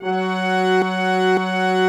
BRASS 3F#4.wav